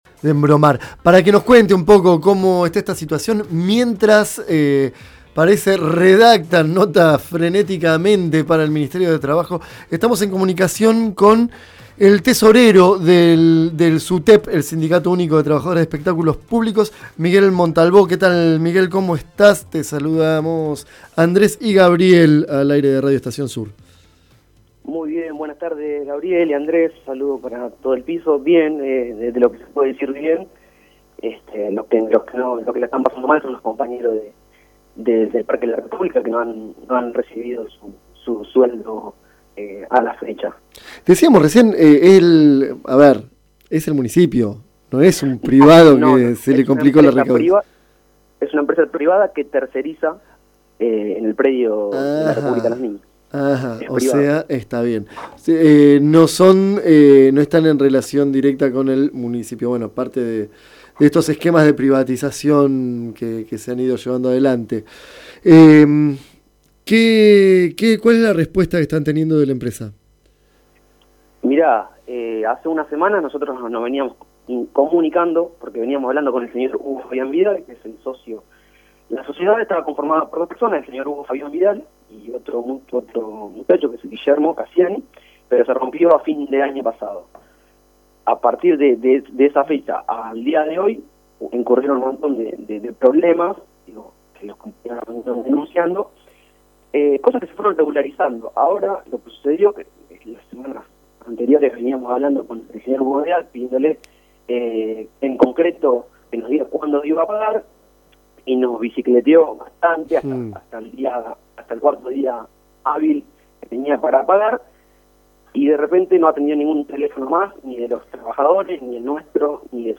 Casi 50 empleados sin cobrar en la República de los Niños | Radio Estación Sur